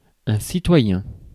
Ääntäminen
IPA: [si.twa.jɛ̃]